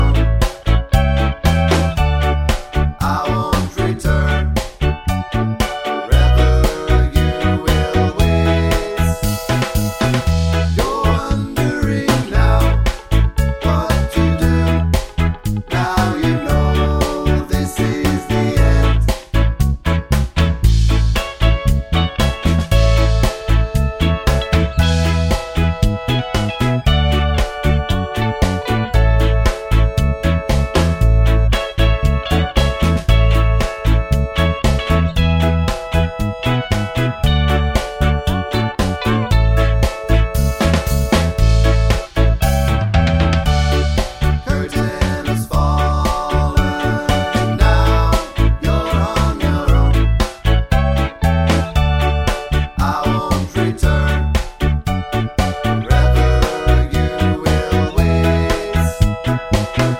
no Backing Vocals Ska 2:31 Buy £1.50